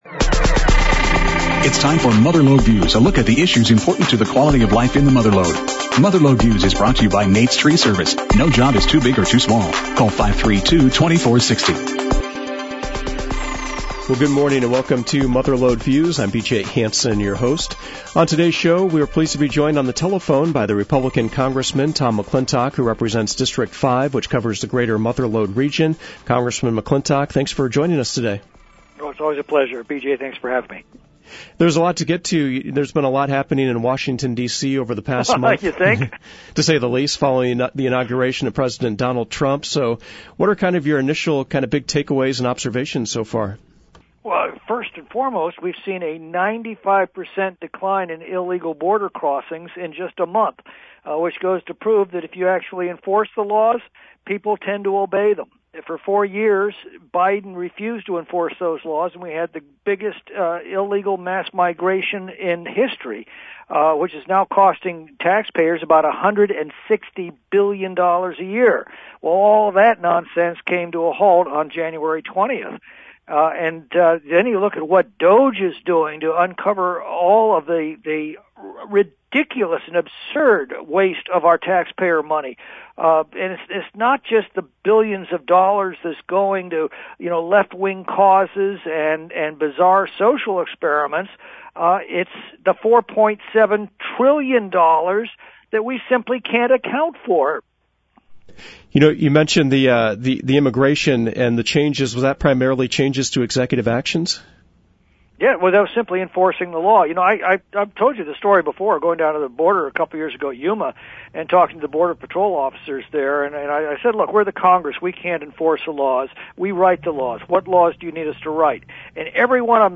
Mother Lode Views featured a conversation with District Five Republican Congressman Tom McClintock. The focus of the interview will be actions taken during the first month of President Donald Trump’s new term in office. He talked about the Department of Government Efficiency (DOGE), the Yosemite Reservation System, Immigration, and other topics.